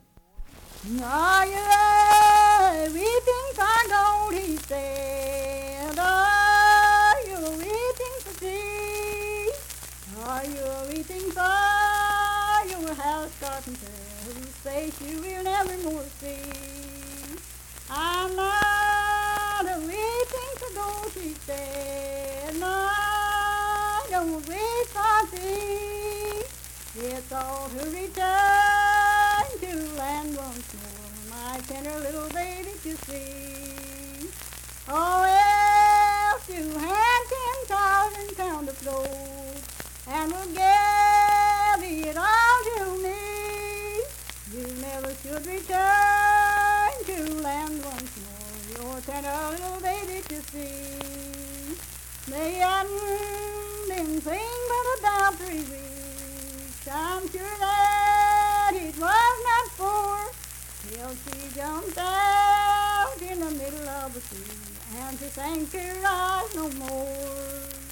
Unaccompanied vocal music performance
Verse-refrain 11(4).
Folk music--West Virginia, Ballads
Voice (sung)